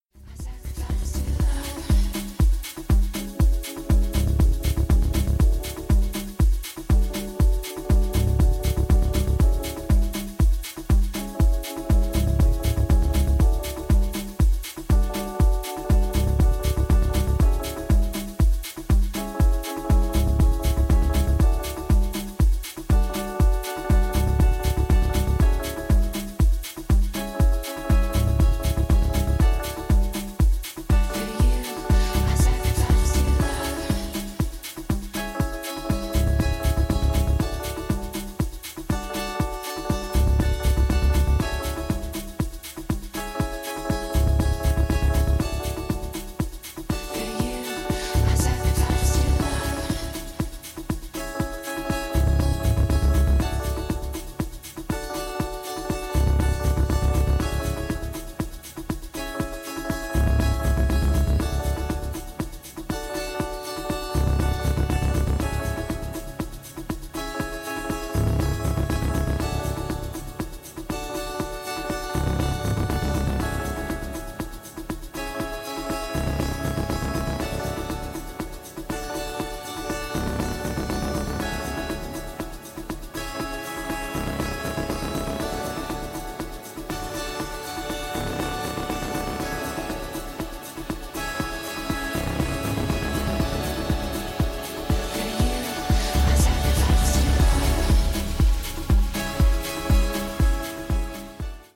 a flowing and densely arranged dancefloor meditation